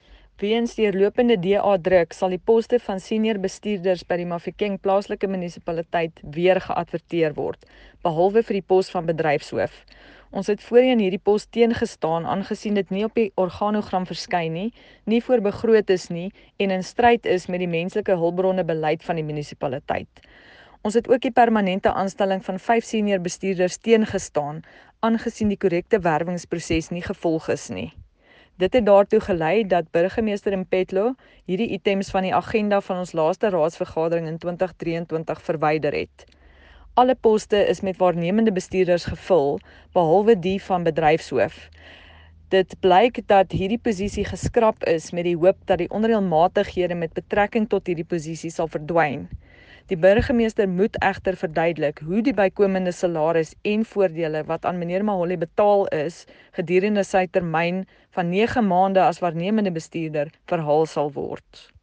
Note to Broadcasters: Please find linked soundbites in
Rdl-Arista-Annandale-Mahikeng-Poste-Afr.mp3